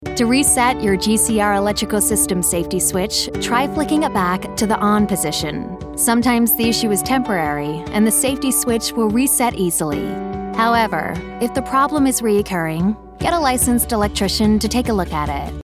Vídeos Explicativos
Neumann U87
Soprano